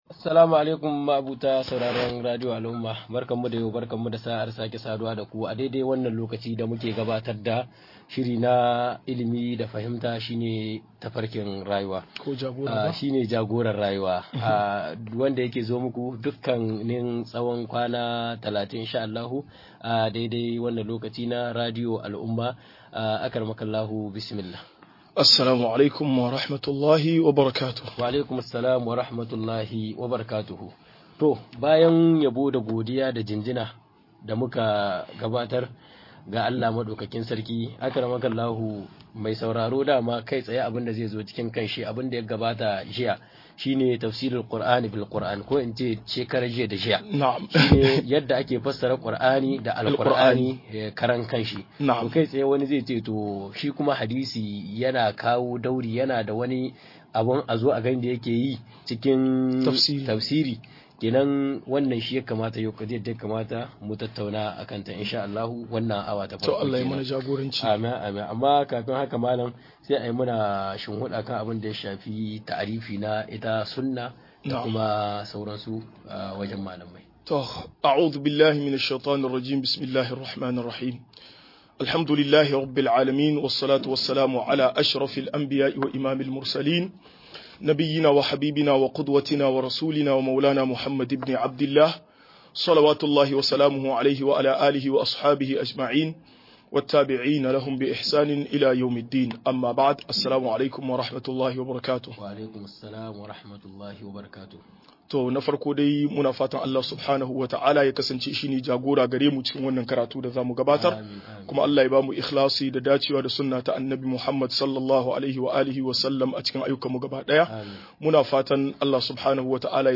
Ka'idodin fassara Alkur'ani da sunnh - MUHADARA